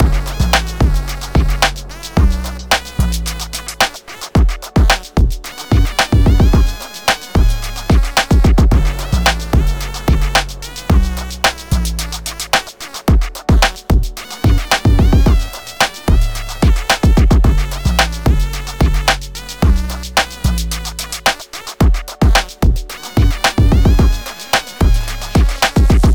Gb Minor
Frumpy 808
Game Voice
Mushroom Top
Clap Splinter